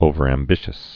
(ōvər-ăm-bĭshəs)